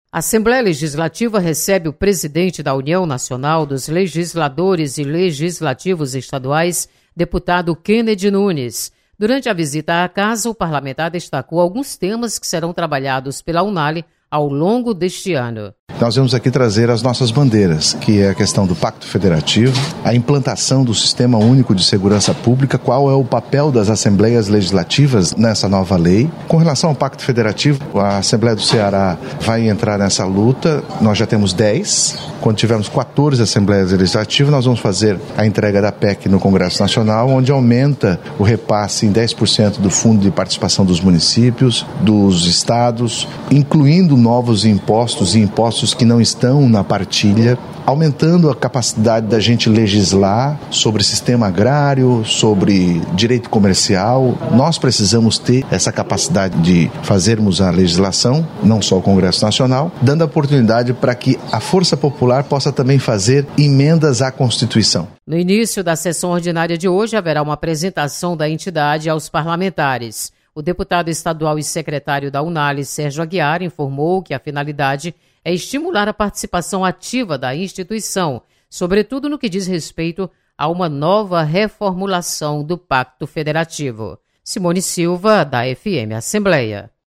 Presidente da Unale visita assembleia Legislativa. Repórter